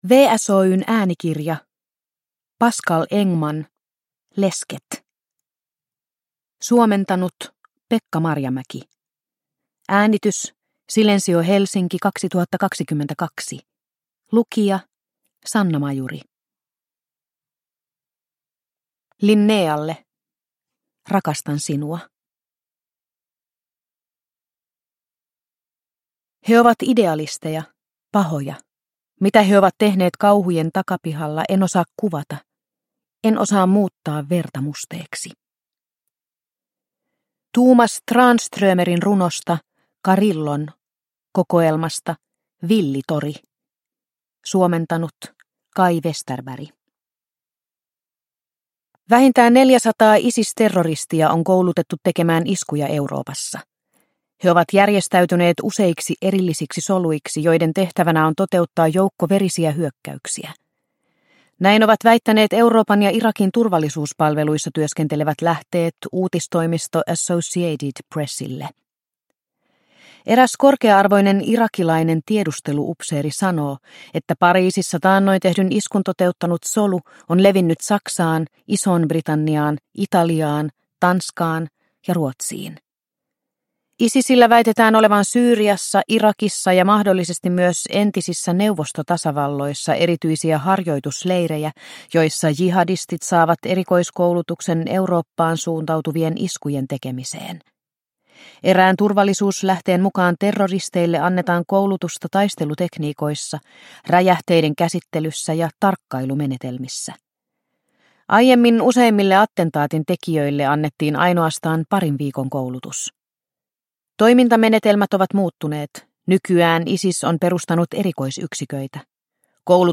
Lesket – Ljudbok – Laddas ner